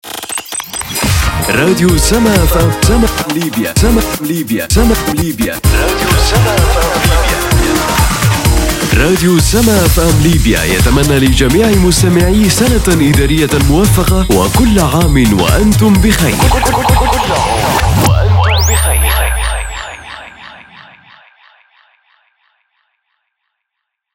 I am a professional arabic voice over talent.
Sprechprobe: Werbung (Muttersprache):